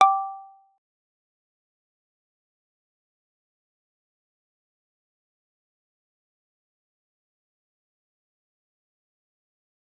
G_Kalimba-G6-mf.wav